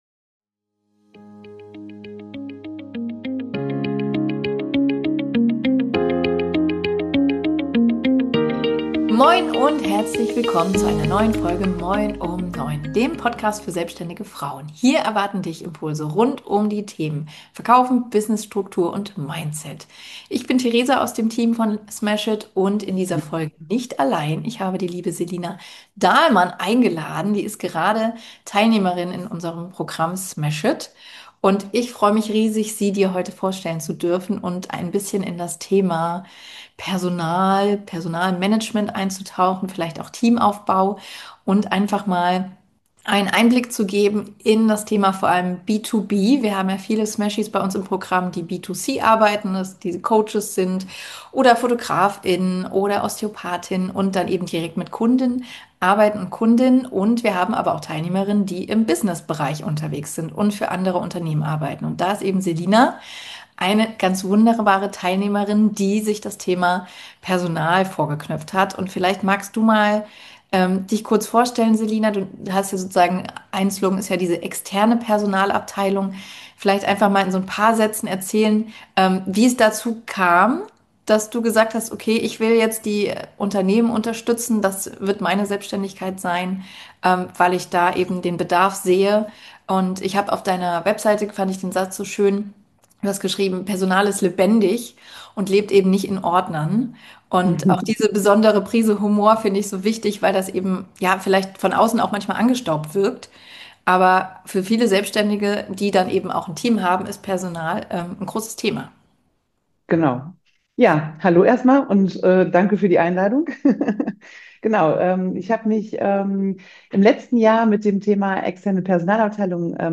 Gemeinsam sprechen die beiden über die vielen kleinen Fragen, die plötzlich auftauchen, wenn dein Business wächst. Wann ist eigentlich der richtige Zeitpunkt für Personal? Wie findest du die richtige Person?